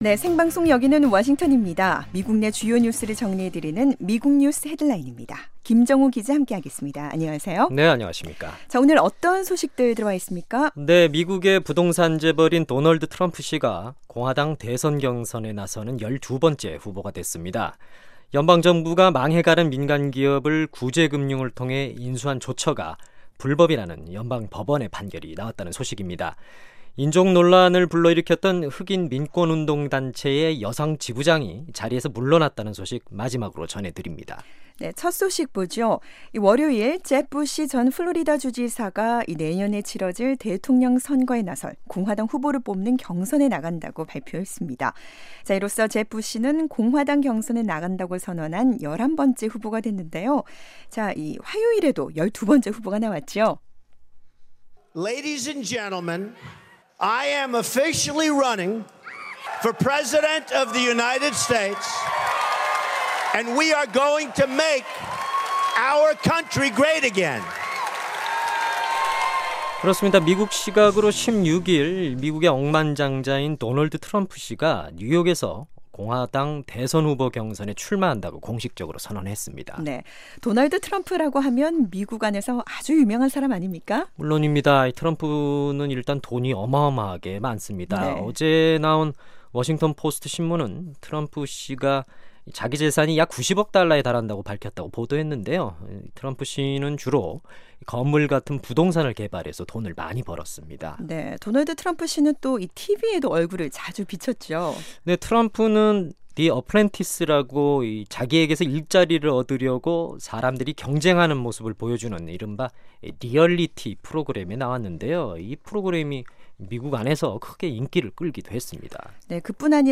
미국 내 주요 뉴스를 정리해 드리는 ‘미국 뉴스 헤드라인’입니다. 미국의 부동산 재벌인 도널드 트럼프 씨가 공화당 대선 경선에 나서는 12번째 후보가 됐습니다.